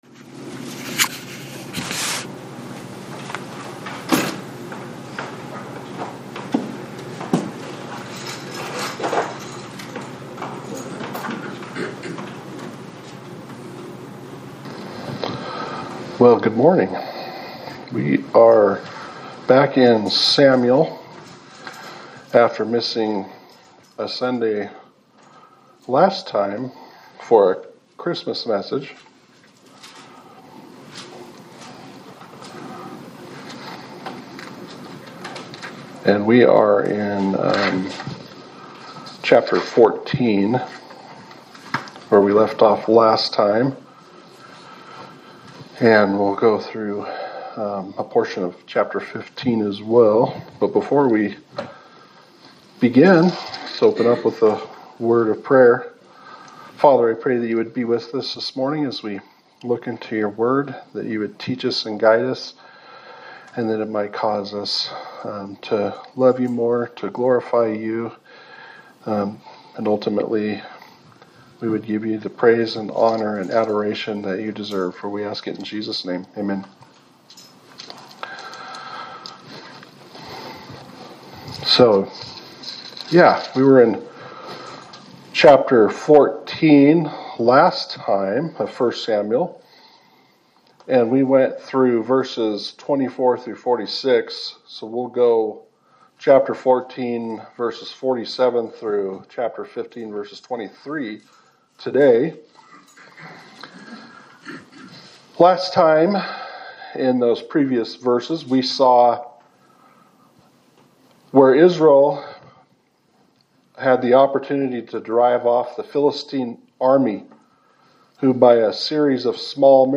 Sermon for January 11, 2026
Service Type: Sunday Service